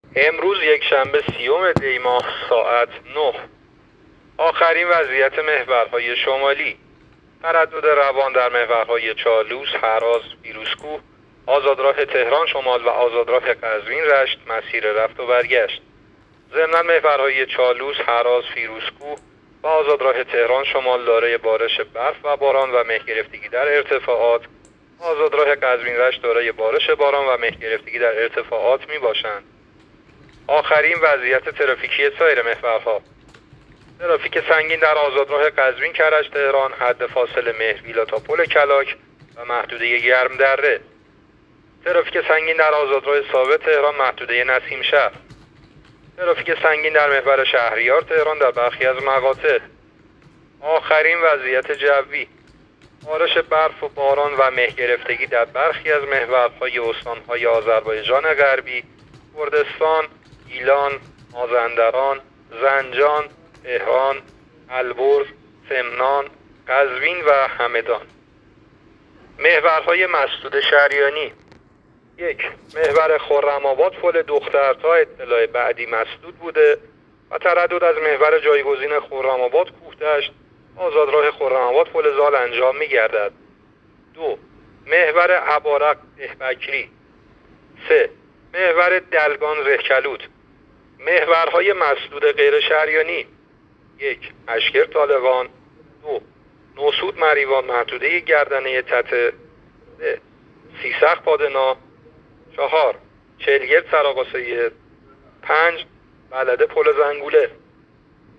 گزارش رادیو اینترنتی از آخرین وضعیت ترافیکی جاده‌ها تا ساعت ۹ سی ام دی؛